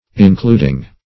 Including - definition of Including - synonyms, pronunciation, spelling from Free Dictionary